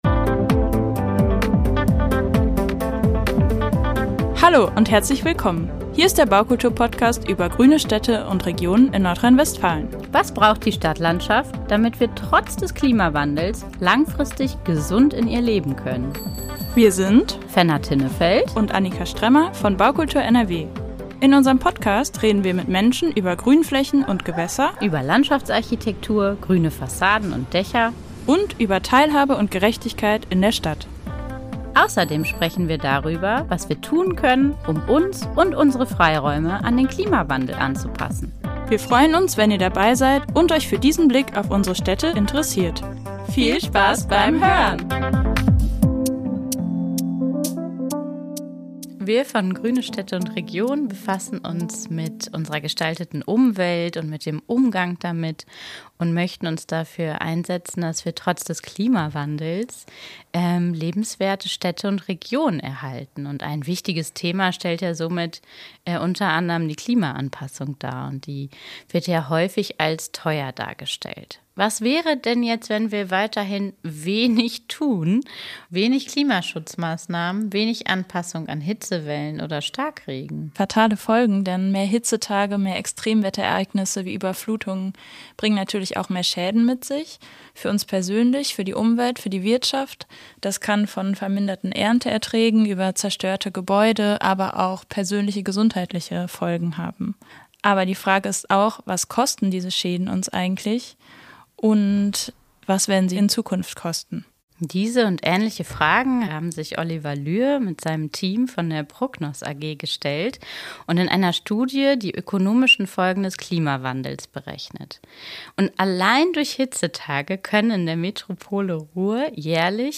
Klimaanpassung kostet – aber Nichtstun noch viel mehr. Ein Gespräch